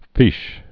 (fēsh)